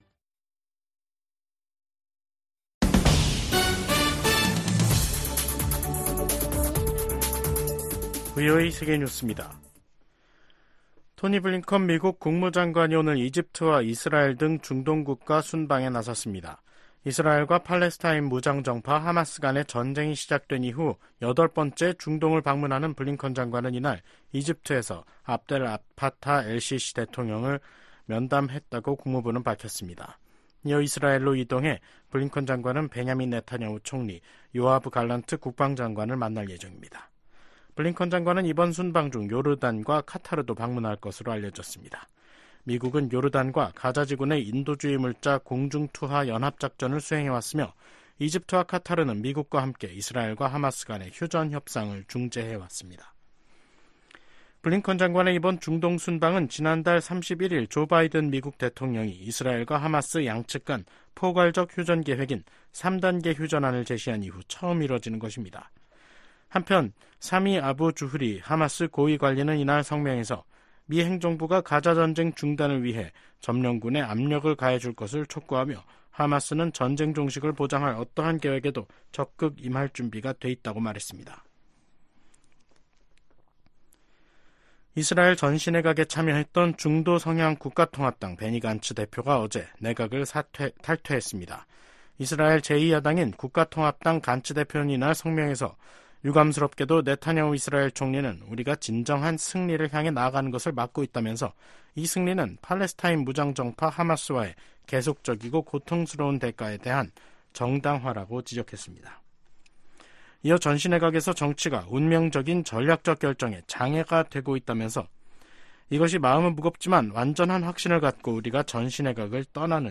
VOA 한국어 간판 뉴스 프로그램 '뉴스 투데이', 2024년 6월 10일 3부 방송입니다. 김여정 북한 노동당 부부장은 한국이 전단 살포와 확성기 방송을 병행하면 새로운 대응에 나서겠다고 위협했습니다. 한국 정부는 한국사회에 혼란을 야기하는 북한의 어떤 시도도 용납할 수 없다고 경고했습니다. 미국의 백악관 국가안보보좌관이 북한, 중국, 러시아 간 핵 협력 상황을 면밀이 주시하고 있다고 밝혔습니다.